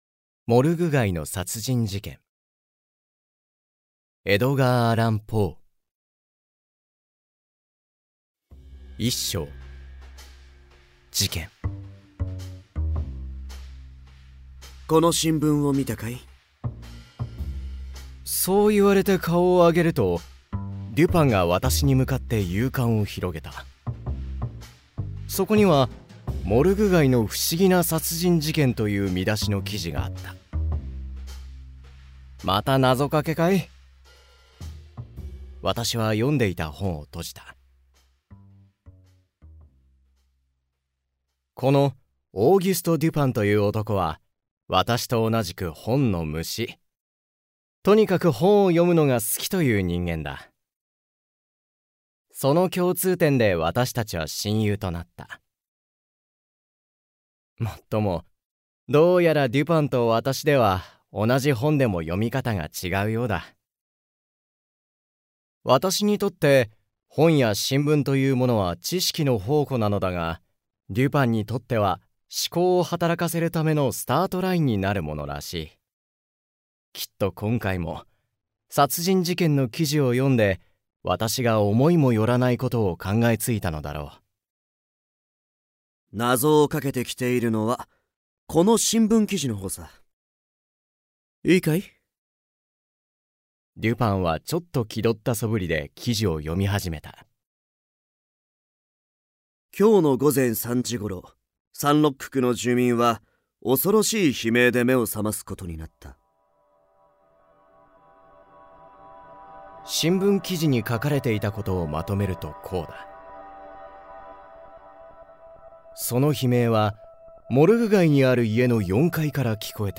[オーディオブック] モルグ街の殺人事件（こどものための聴く名作45）